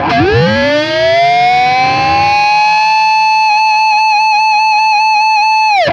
DIVEBOMB21-L.wav